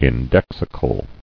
[in·dex·i·cal]